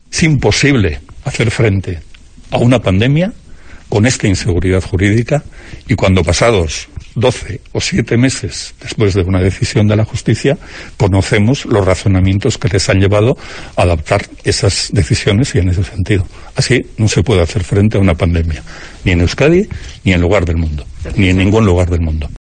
Bingen Zupiria, portavoz del Gobierno Vasco